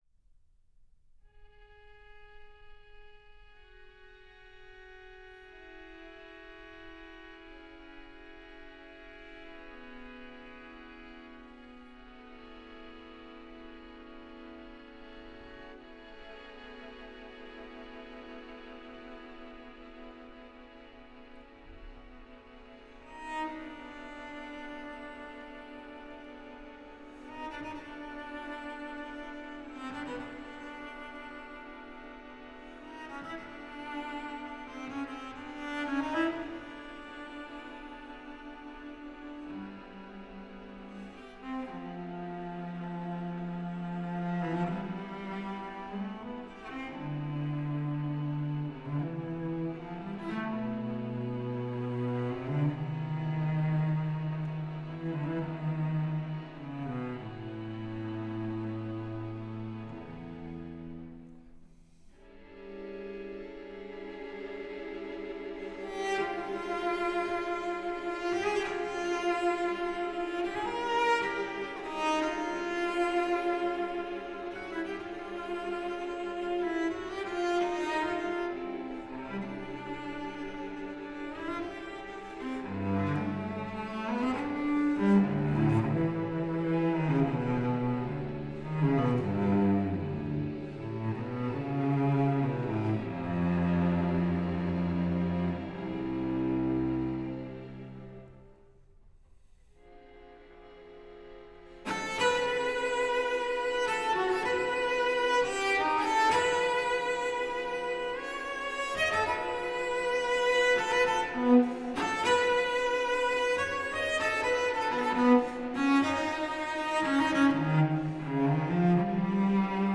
One last Bartók record I really like is relatively maximalist, called Bartók: 6 String Quartets by the Tákacs Quartet, (Decca 1997.)  It’s a more consistent record than the piano performances, and a lot of it is just gorgeous, (see link below.)